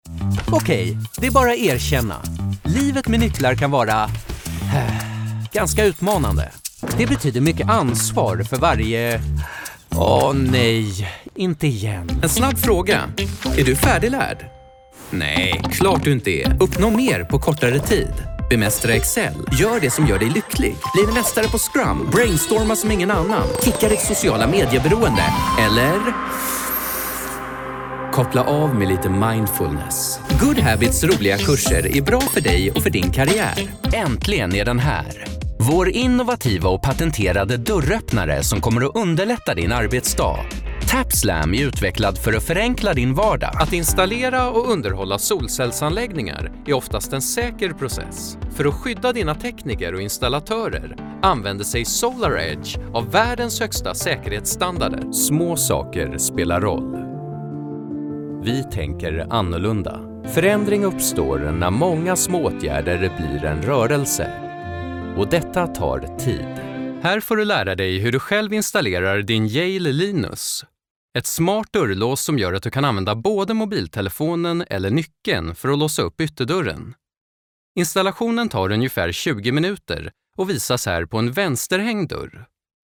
Enjouée, Chaude, Commerciale, Naturelle, Amicale
Corporate